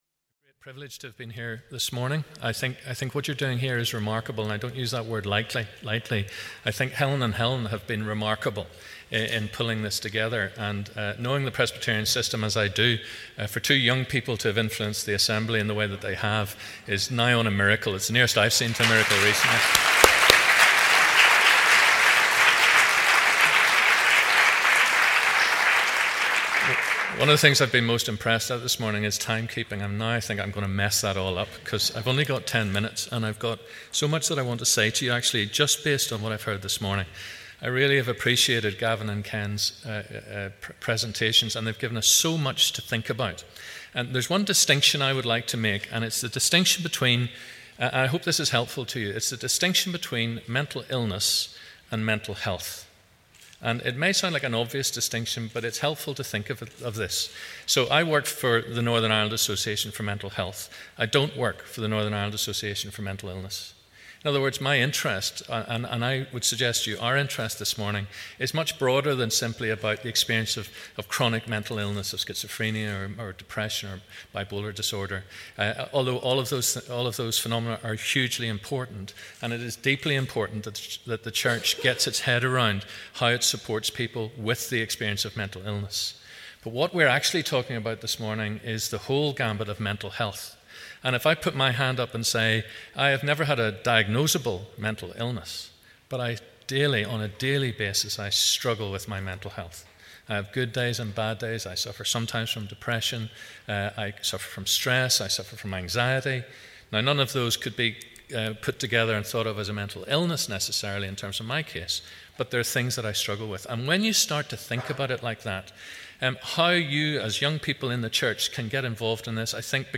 On Saturday, 1st February, around 200 people gathered in Assembly Buildings to “break the silence” on adolescent mental health.